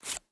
Звуки кошелька
Звук: витягли банківську картку з гаманця